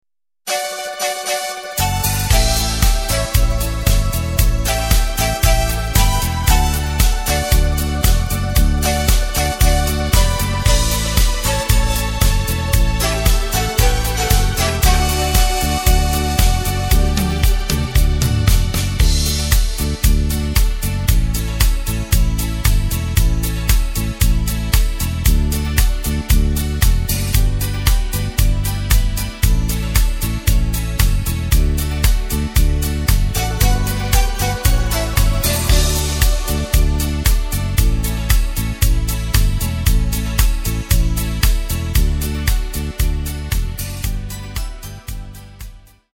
Tempo:         115.00
Tonart:            D
Schlager aus dem Jahr 1996!